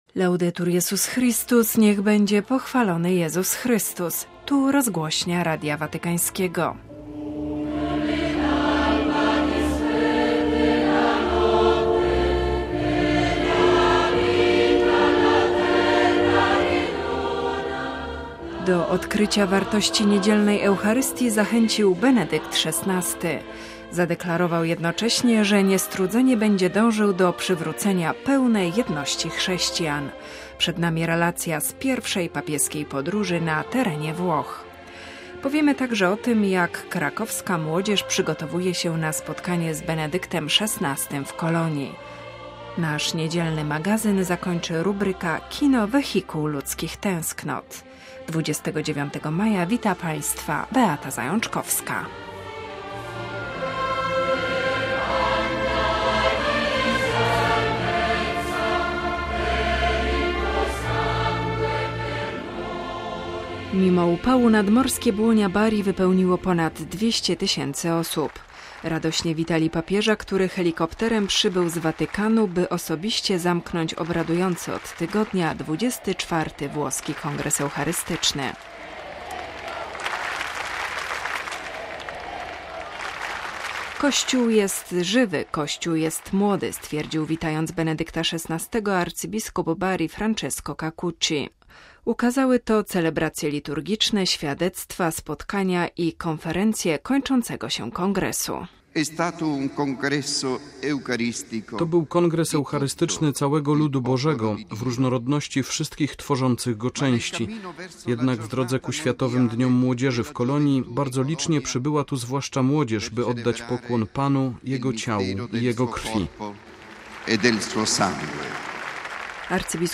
Relacja z pierwszej papieskiej podróży na terenie Włoch. Przygotowania krakowskiej młodzieży na spotkanie z Benedyktem XVI w Kolonii.